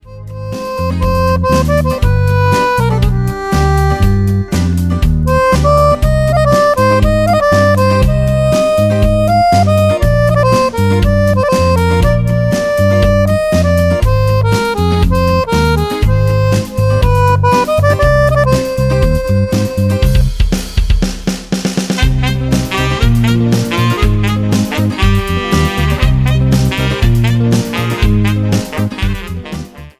BEGUINE  (03.15)